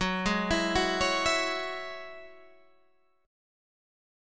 Gb7sus2 chord